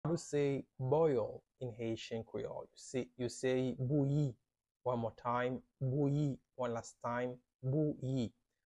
“Boil” in Haitian Creole – “Bouyi” pronunciation by a native Haitian teacher
“Bouyi” Pronunciation in Haitian Creole by a native Haitian can be heard in the audio here or in the video below:
How-to-say-Boil-in-Haitian-Creole-–-Bouyi-pronunciation-by-a-native-Haitian-teacher.mp3